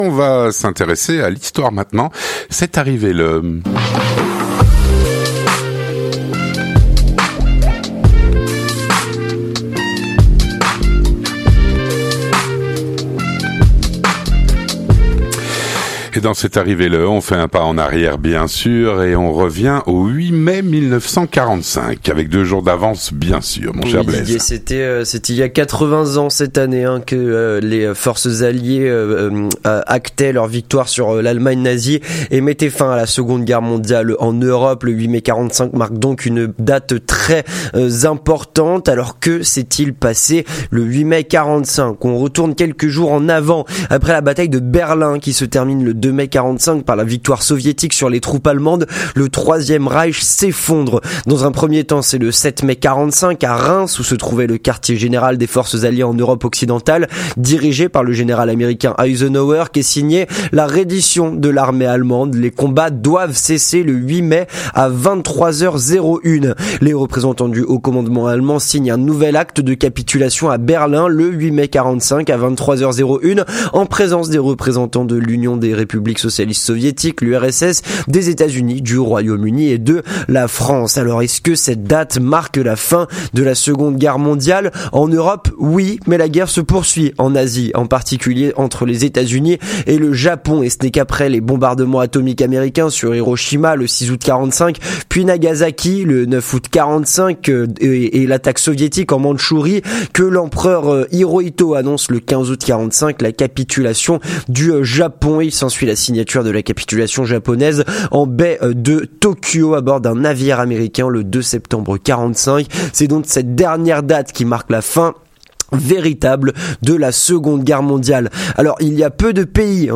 Une chronique